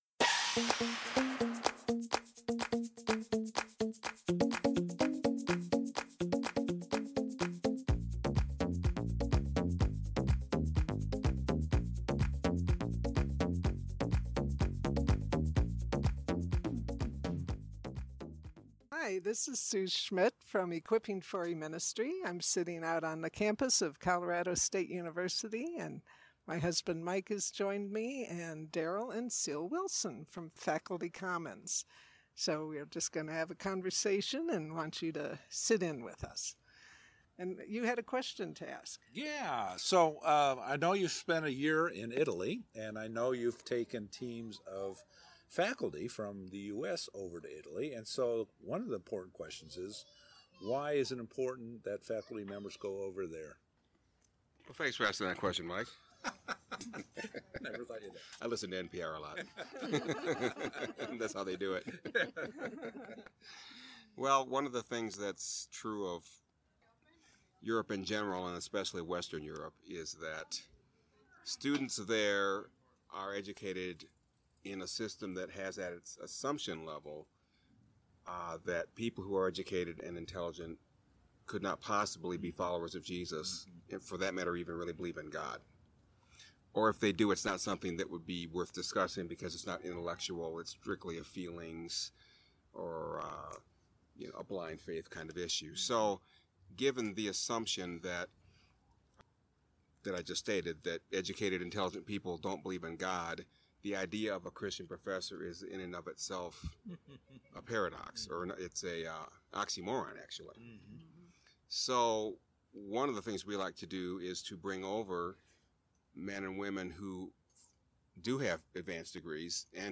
Initially, I’m interviewing Cru staff for e4e podcast content, since the majority of eQuipping for eMinistry’s subscribers are also members of Cru (formerly Campus Crusade for Christ).